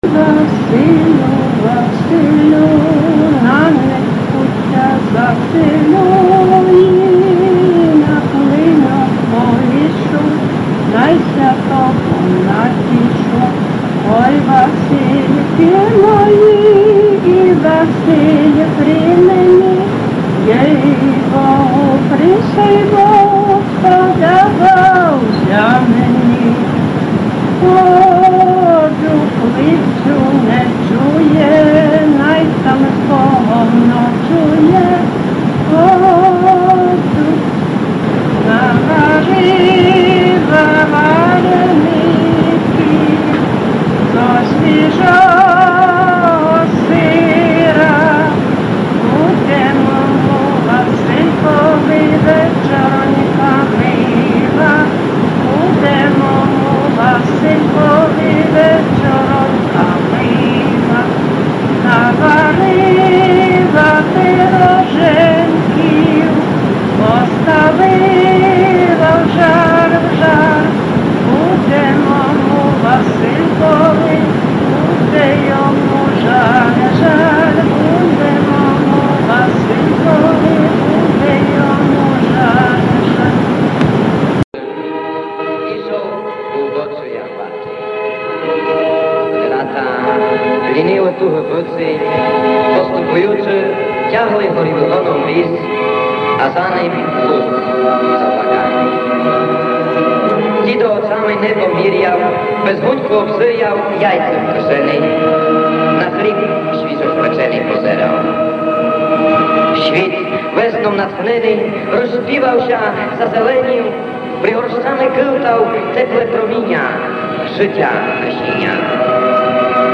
Пісні з Лемківщини записано в Горлицькому повіті селах Устя Руське, Ставиша, Ждиня, Висова.
Фольклорна практика студентів Дрогобицького педінституту. Записано в Лемківщині (Польща)
Pisni-z-Lemkivshhyny-s.mp3